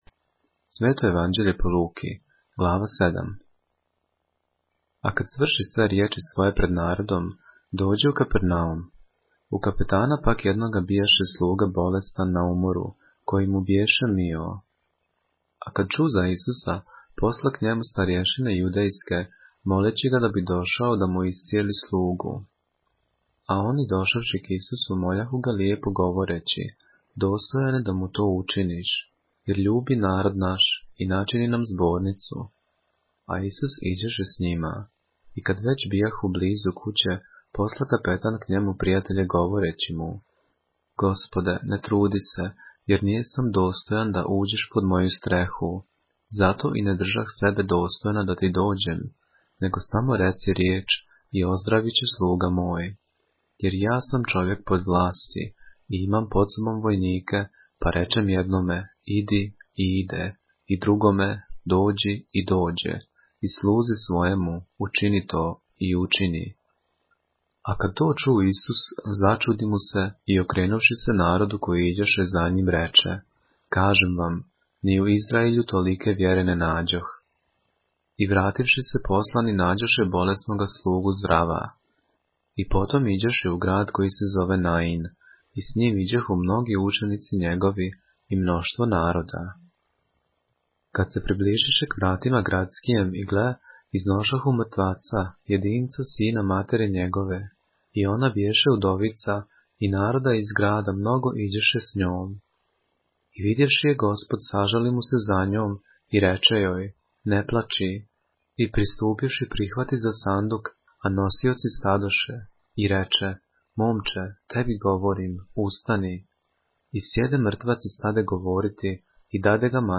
поглавље српске Библије - са аудио нарације - Luke, chapter 7 of the Holy Bible in the Serbian language